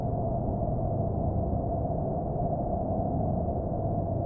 ambientloop1.wav